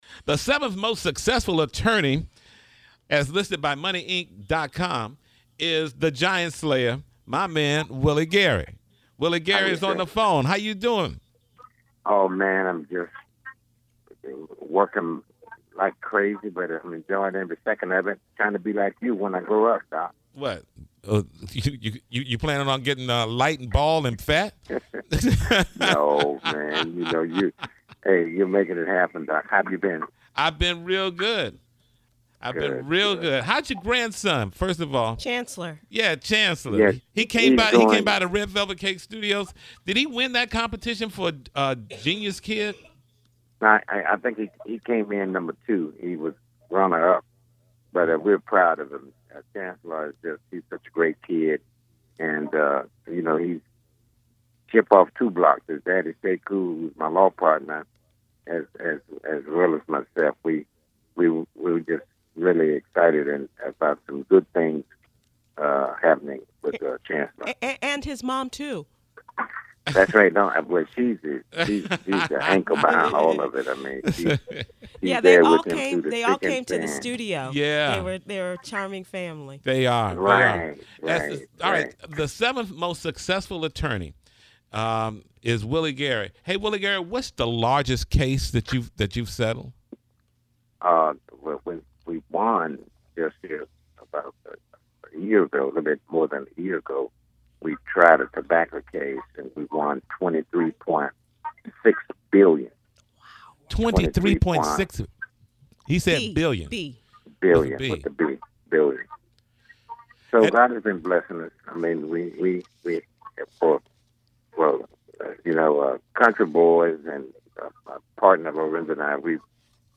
One of the country’s seven most successful attorneys, Willie Gary talks to the Tom Joyner Morning Show about the relevance of the HBCUs in spit of Wendy Williams’ comments (he’s a Shaw University graduate), his biggest case won and the pending lawsuit against the creators of The Real and actress Tatyana Ali.